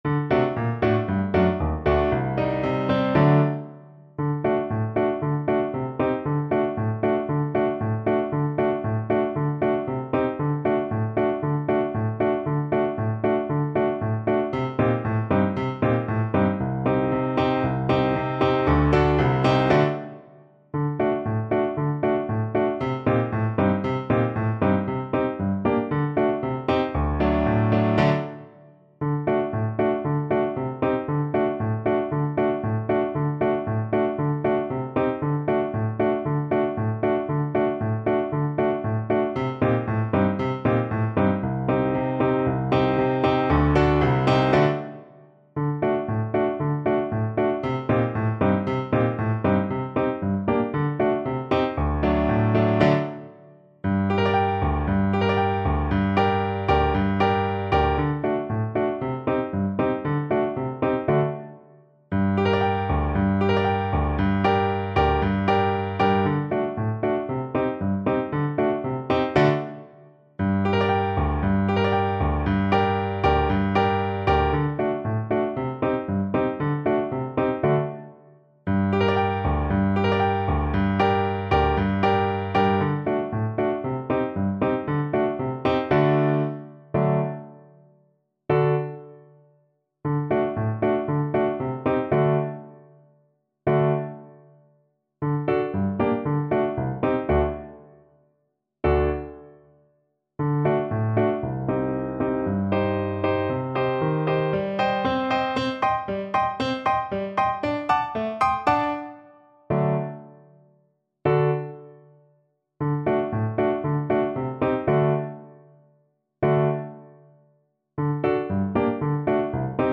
Play (or use space bar on your keyboard) Pause Music Playalong - Piano Accompaniment Playalong Band Accompaniment not yet available transpose reset tempo print settings full screen
2/4 (View more 2/4 Music)
G minor (Sounding Pitch) (View more G minor Music for Flute )
Allegro =c.116 (View more music marked Allegro)